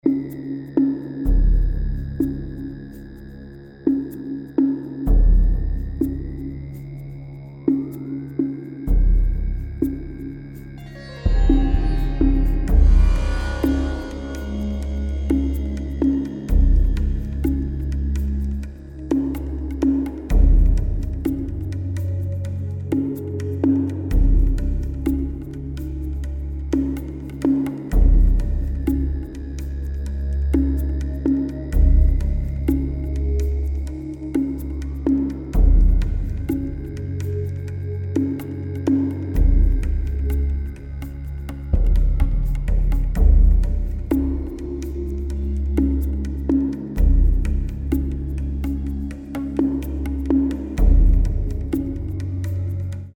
Tempo: 62 - 66